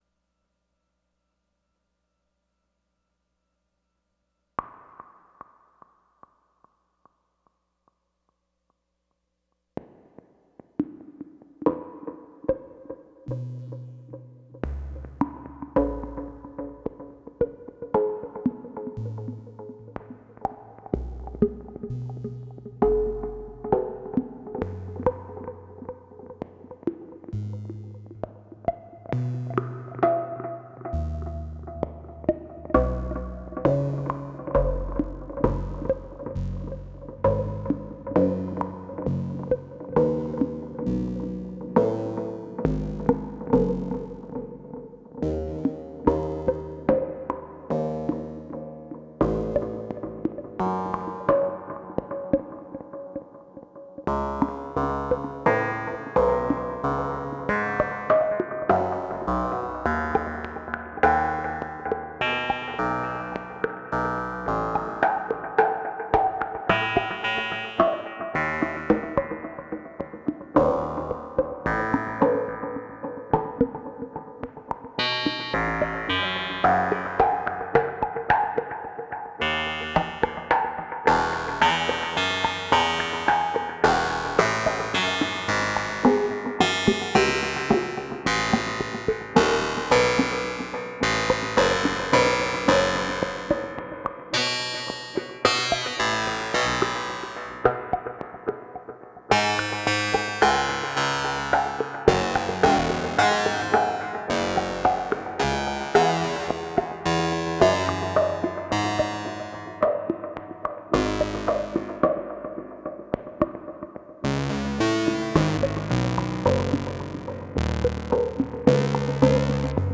Couple of things with Generate 3, Paradox, and Three Sisters. One is a drone étude. The other pings 3S and Paradox’s PWM for percussion, with a G3 TZFM lead on top. SA Collider and Analog Heat at end of chain.